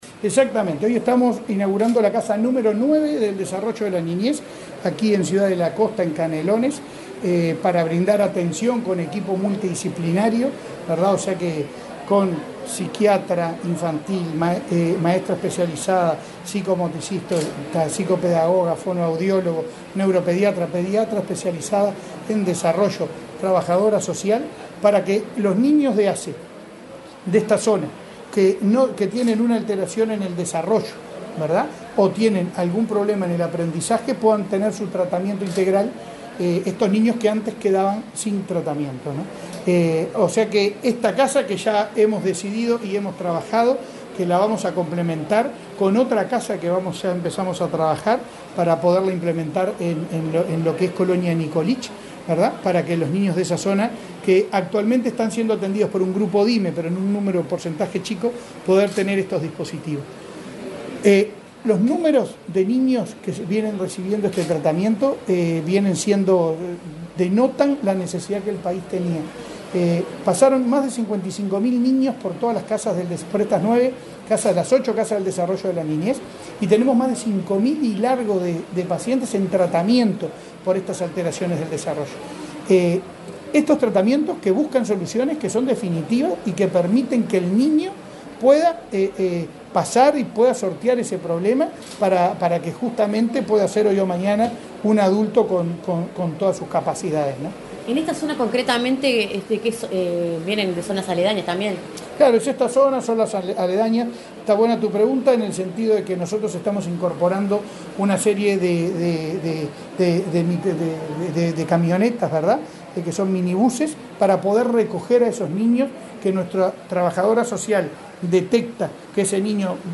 Declaraciones del presidente de ASSE, Leonardo Cipriani
El presidente de la Administración de los Servicios de Salud del Estado (ASSE), Leonardo Cipriani, dialogó con Comunicación Presidencial, este martes